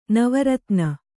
♪ nava ratna